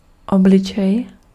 Ääntäminen
IPA: [vi.zaʒ]